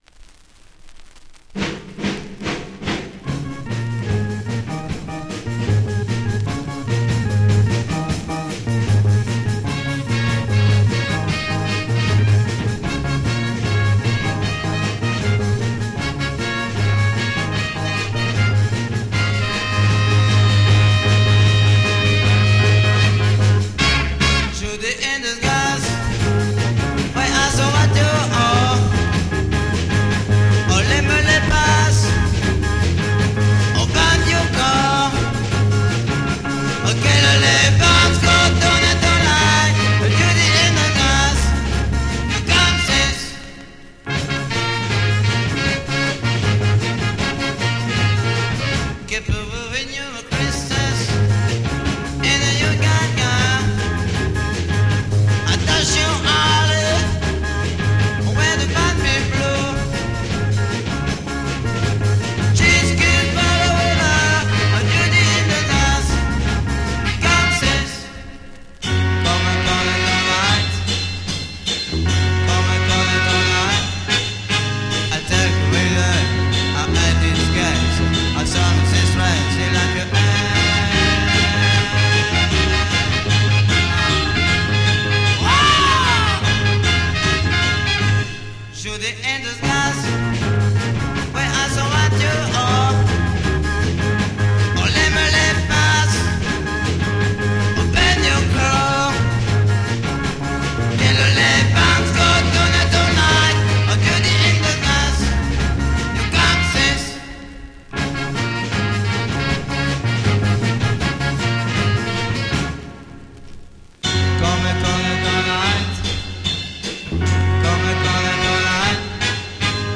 low-fi recording with some nice organ sound